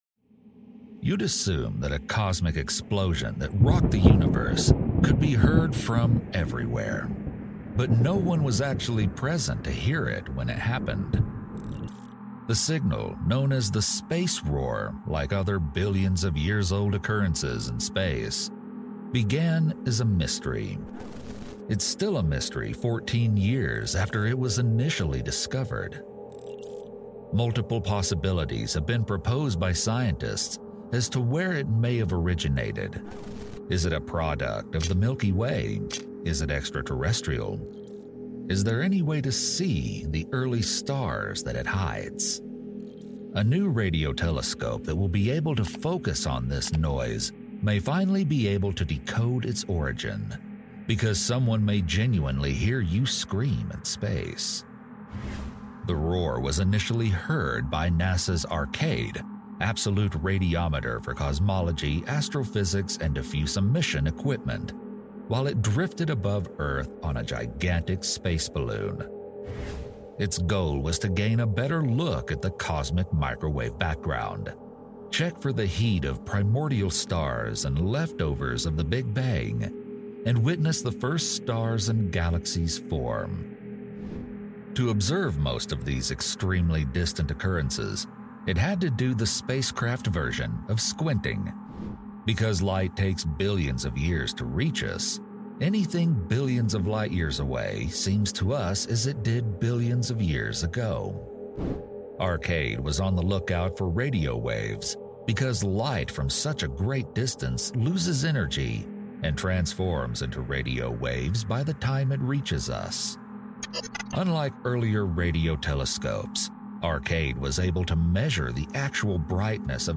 Space Roar: The Entire Universe sound effects free download
The Entire Universe Mp3 Sound Effect Space Roar: The Entire Universe Is Alive With Sound.